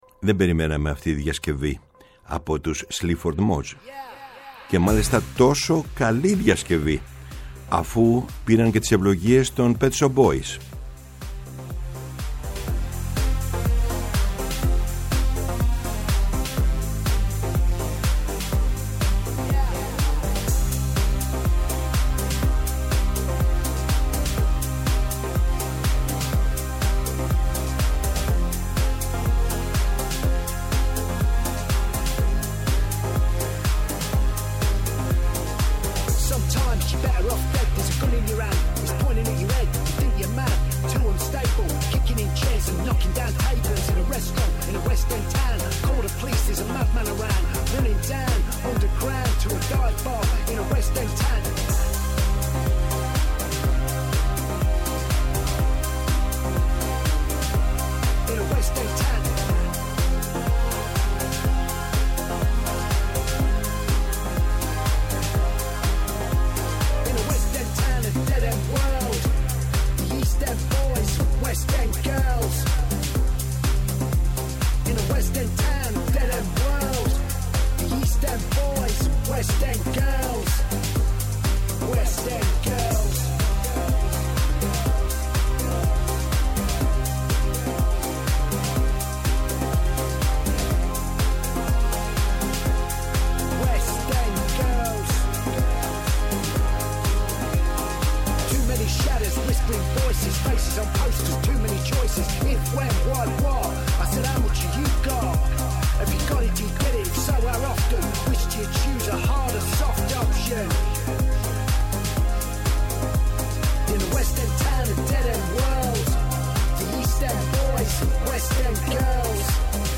Η μακροβιότερη εκπομπή στο Ελληνικό Ραδιόφωνο!
Από το 1975 ως τον Ιούνιο του 2013 και από το 2017 ως σήμερα, ο Γιάννης Πετρίδης βρίσκεται στις συχνότητες της Ελληνικής Ραδιοφωνίας, καθημερινά «Από τις 4 στις 5» το απόγευμα, στο Πρώτο Πρόγραμμα.